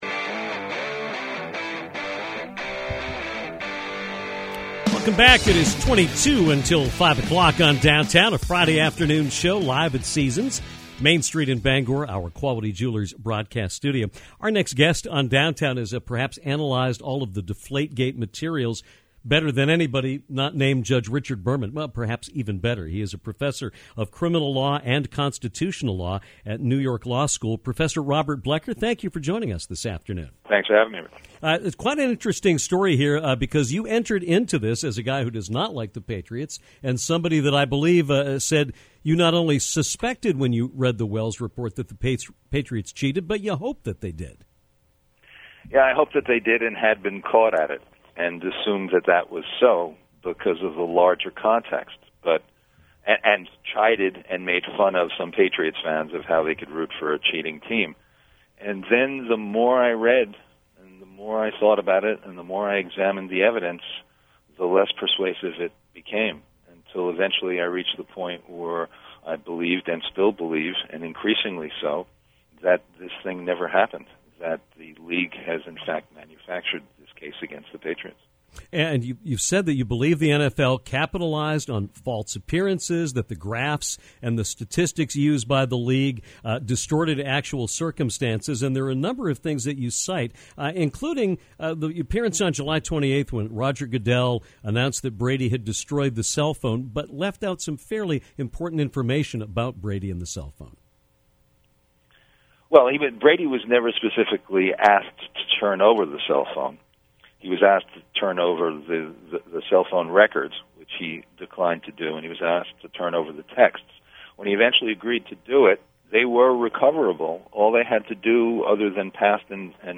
Listen to the full interview below and judge for yourself about the deflate-gate saga.